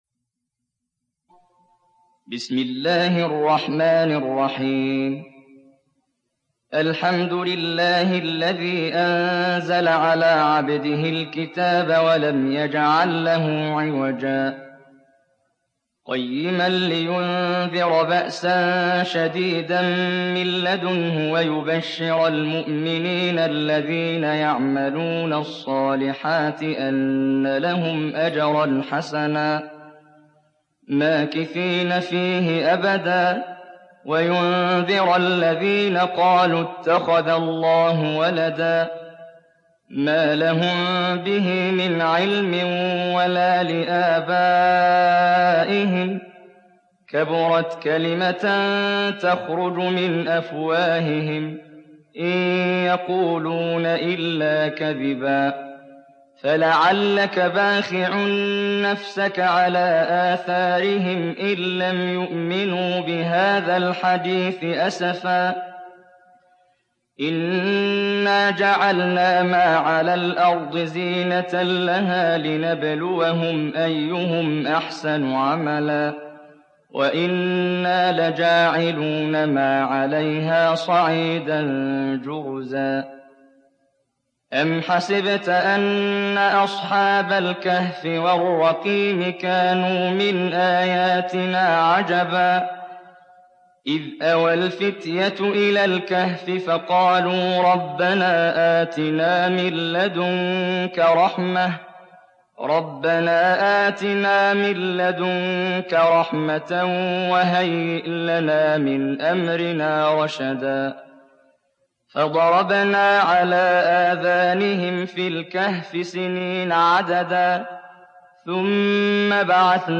تحميل سورة الكهف mp3 بصوت محمد جبريل برواية حفص عن عاصم, تحميل استماع القرآن الكريم على الجوال mp3 كاملا بروابط مباشرة وسريعة